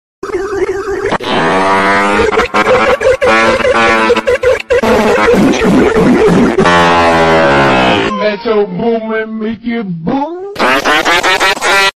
meme sound board Brain Fart (Metro sound effects free download